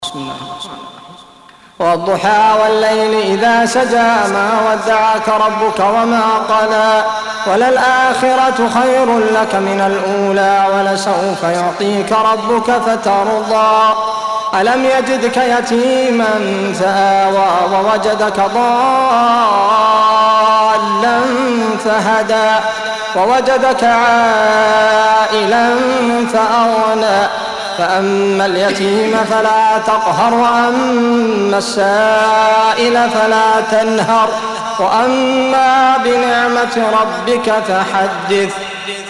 93. Surah Ad-Duha سورة الضحى Audio Quran Tarteel Recitation
Surah Sequence تتابع السورة Download Surah حمّل السورة Reciting Murattalah Audio for 93. Surah Ad-Duha سورة الضحى N.B *Surah Includes Al-Basmalah Reciters Sequents تتابع التلاوات Reciters Repeats تكرار التلاوات